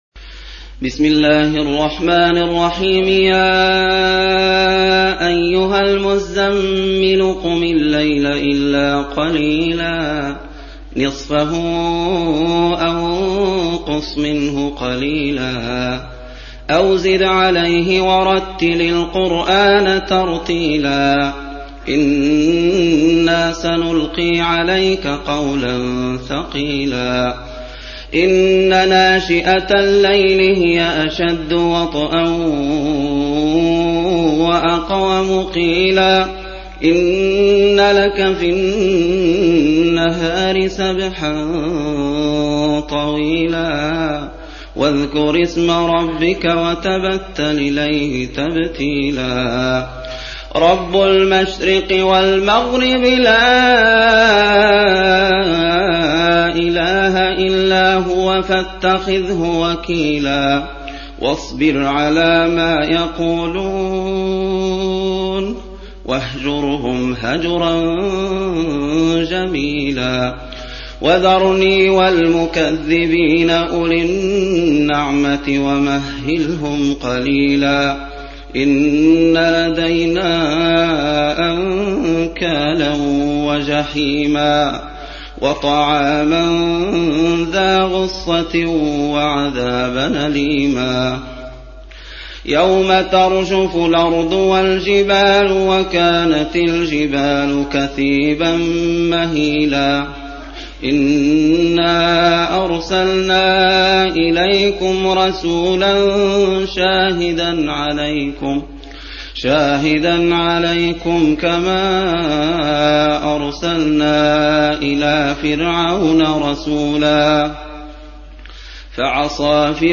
73. Surah Al-Muzzammil سورة المزّمّل Audio Quran Tarteel Recitation
Surah Sequence تتابع السورة Download Surah حمّل السورة Reciting Murattalah Audio for 73. Surah Al-Muzzammil سورة المزّمّل N.B *Surah Includes Al-Basmalah Reciters Sequents تتابع التلاوات Reciters Repeats تكرار التلاوات